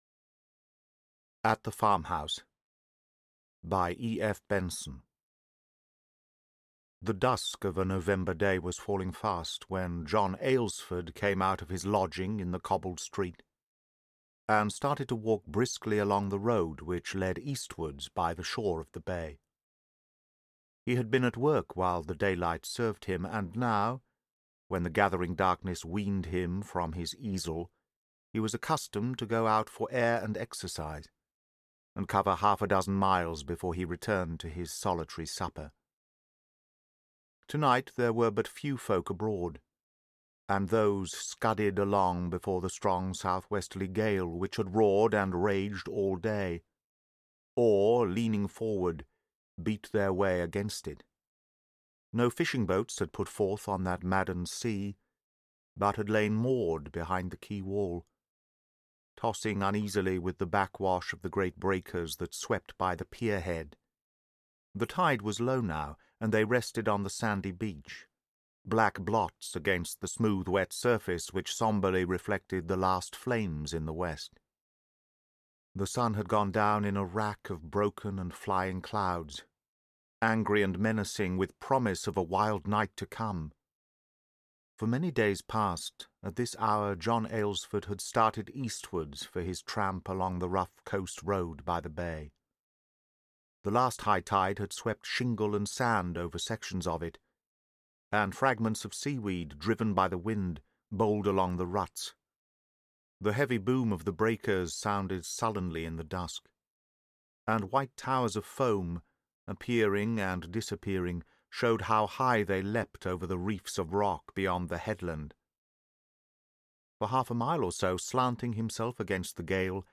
The Captain of the Polestar: Adventure and Danger (Audiobook)